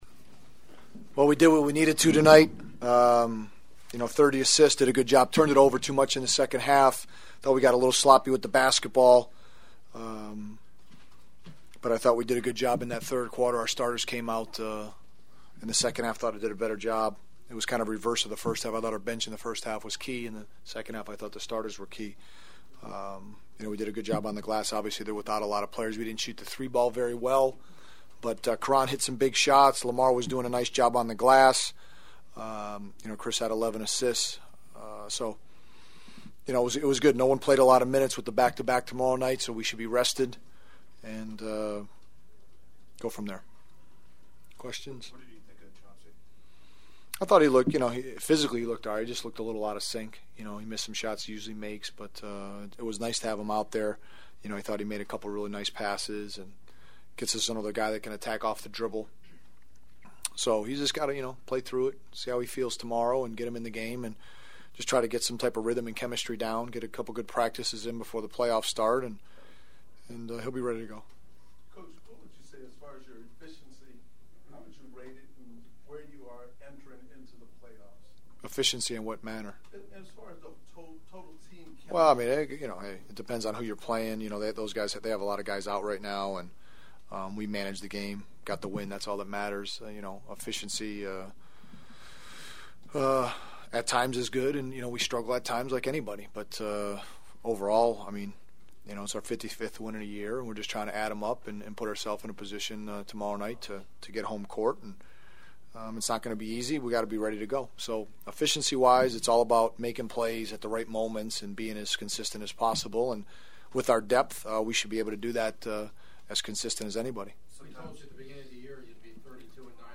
Coach Vinny Del Negro’s postgame newser sounded cautiously optimistic just wanting to take care of business in Sacto first before getting ahead of themselves…